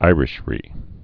(īrĭsh-rē)